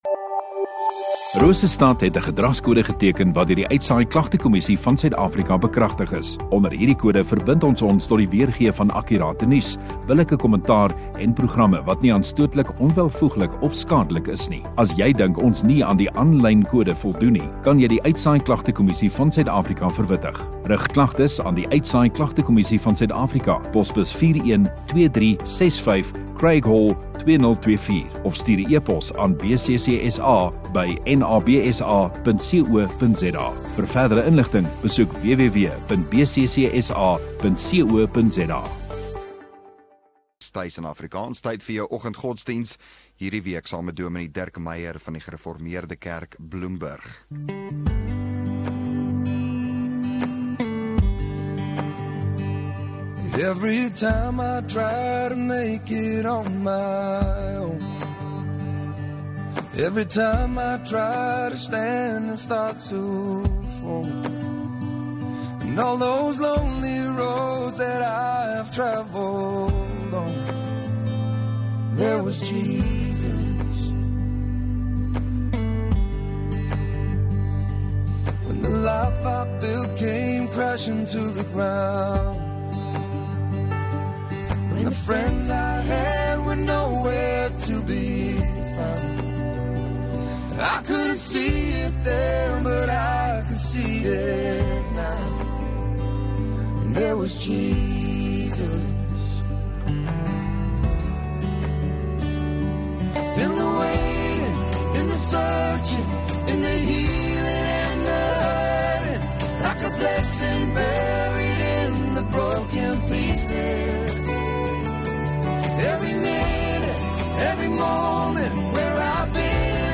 7 Aug Donderdag Oggenddiens